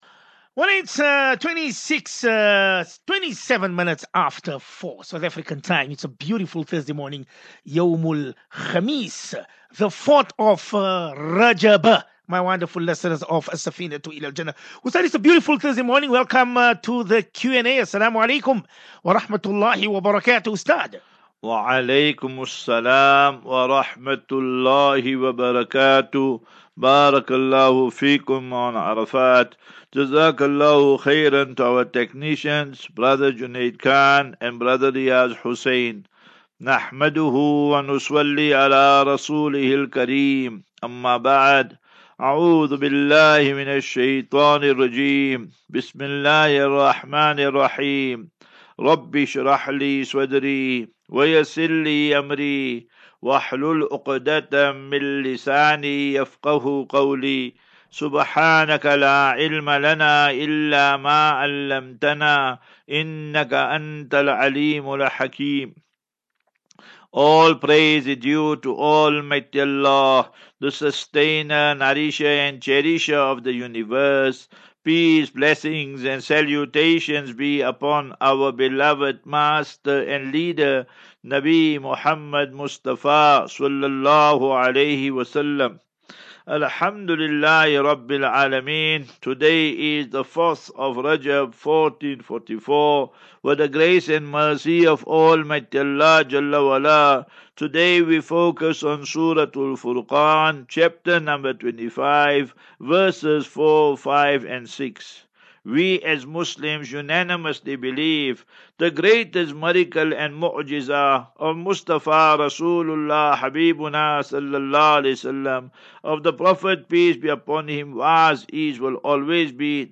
View Promo Continue Install As Safinatu Ilal Jannah Naseeha and Q and A 26 Jan 26 Jan 23- Assafinatu-Illal Jannah 39 MIN Download